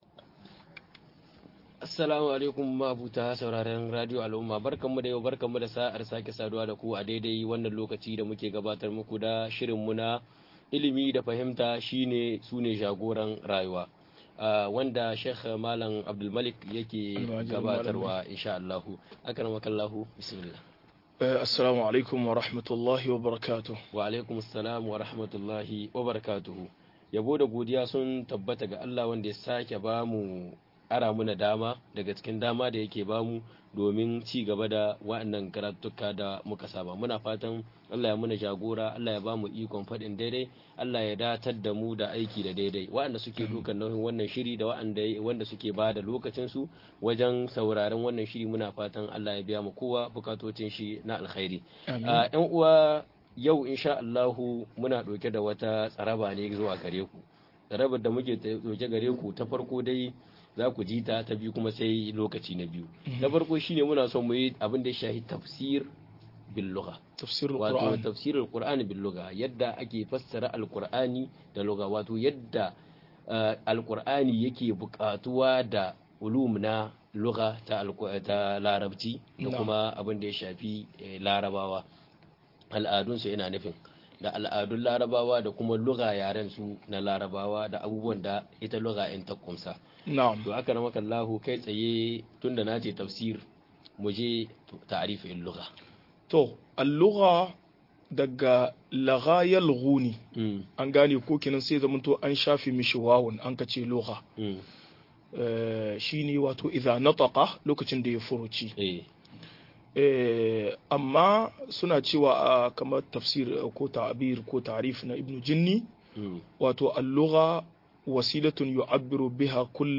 Fassara Alkur'ani da Larabci - MUHADARA